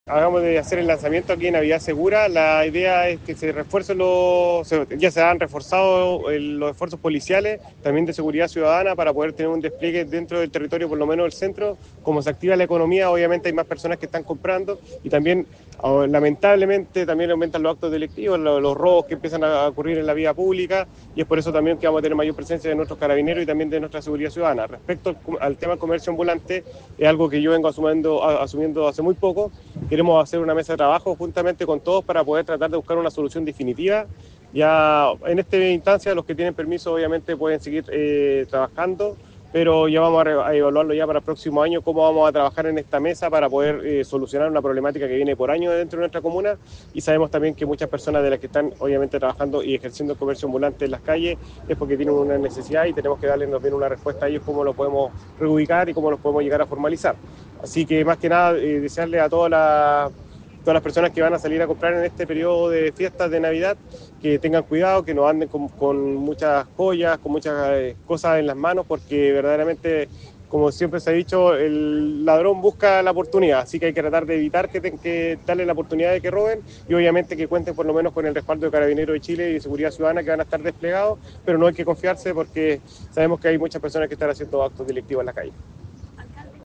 El alcalde de Ovalle, Héctor Vega Campusano, señaló al respecto que
ALCALDE-DE-OVALLE-HECTOR-VEGA.mp3